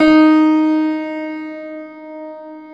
53c-pno11-D2.wav